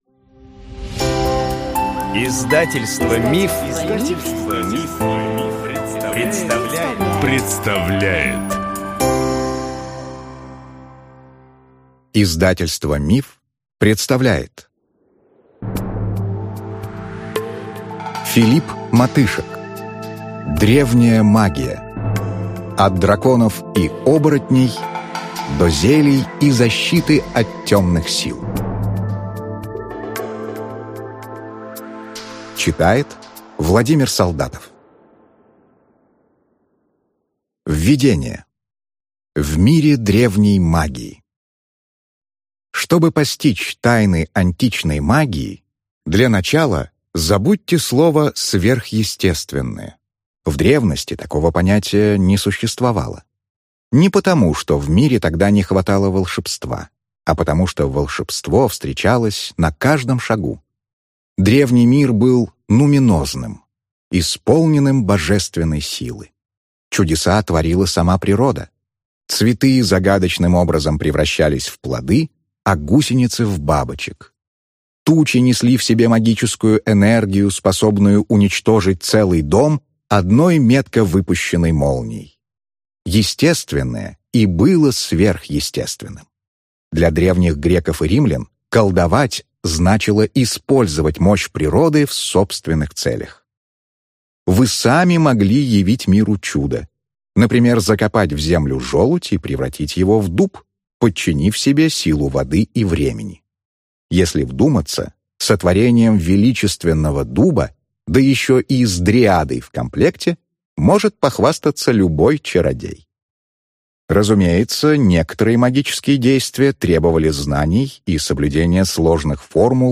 Аудиокнига Древняя магия. От драконов и оборотней до зелий и защиты от темных сил | Библиотека аудиокниг